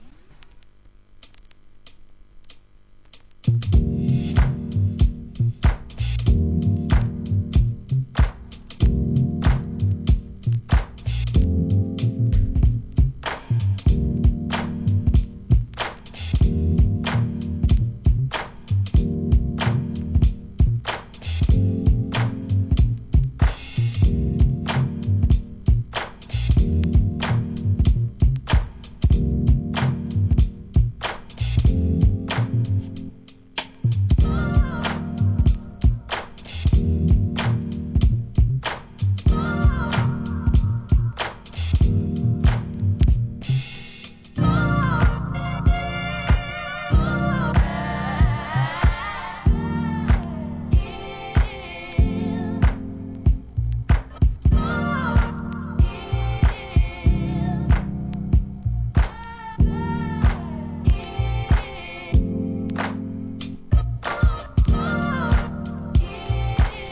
HIP HOP/R&B
ノルウェー発ブレイクビーツ・コンピレーション!